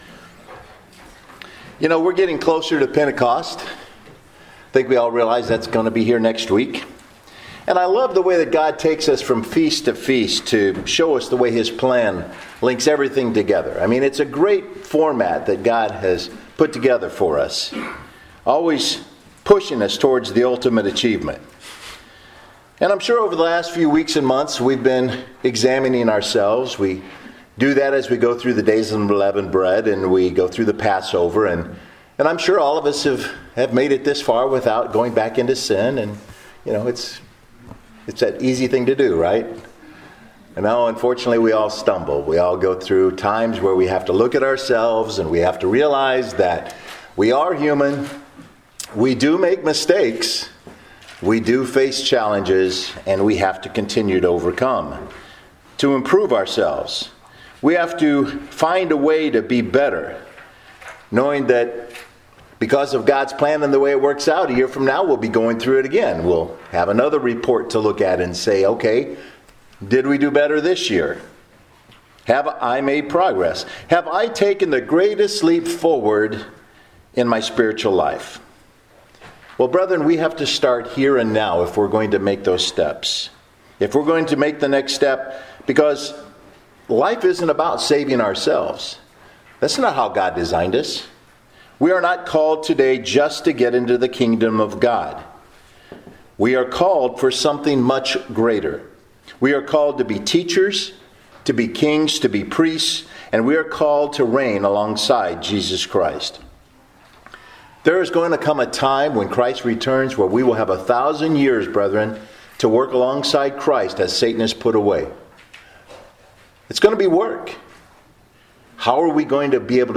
Given in Murfreesboro, TN